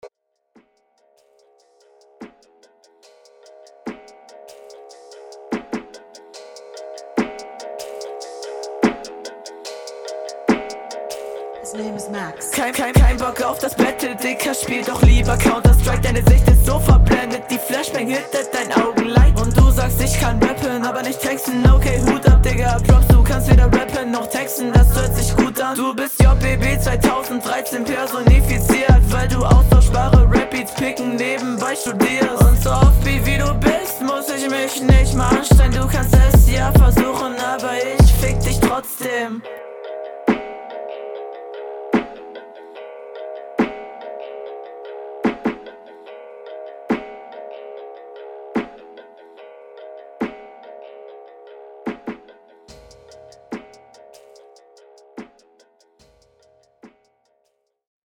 Flow: Flowvarationen sind cool, flow angenehm wie immer Text: Ist jetzt kein krasses Werk aber …